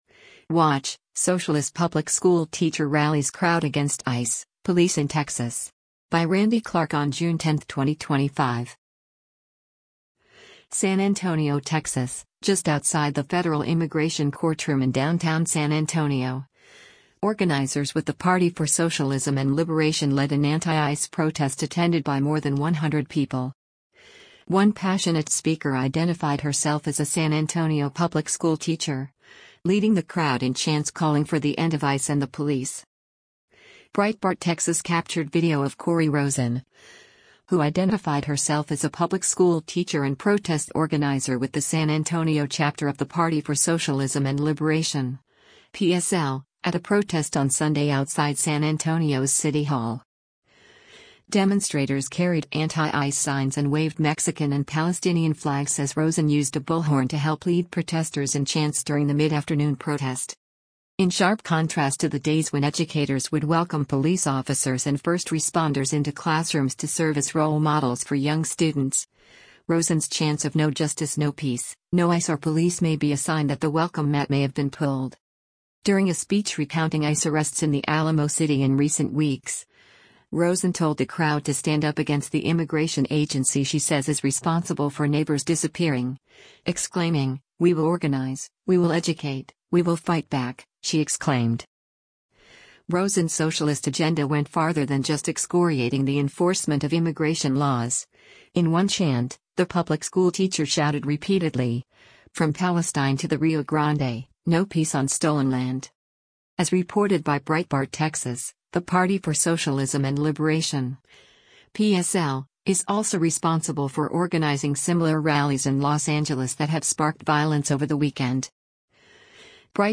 SAN ANTONIO, Texas — Just outside the federal immigration courtroom in downtown San Antonio, organizers with the Party for Socialism and Liberation led an anti-ICE protest attended by more than 100 people.